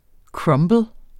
Udtale [ ˈkɹʌmbəl ]